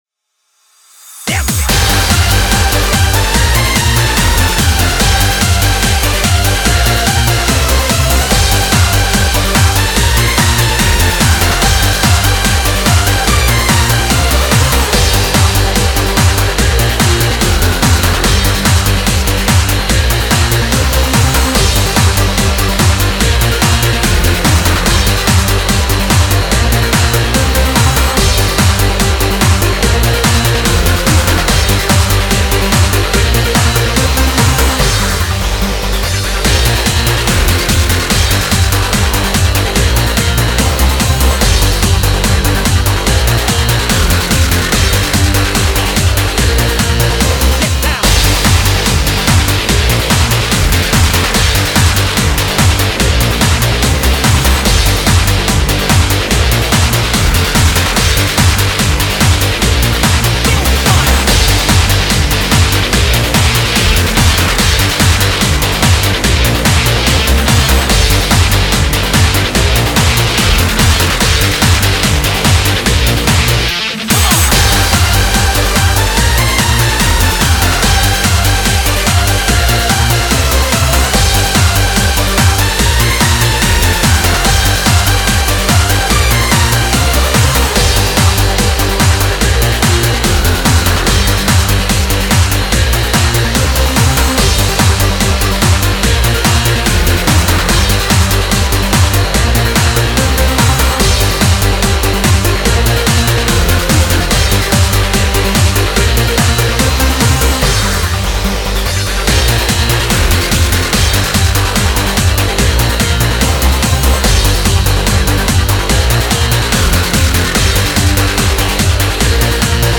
faster tempo, no drum, several instruments removed
might be loud on some devices though
bad mastering and mixing
bpm is 145
Music / Trance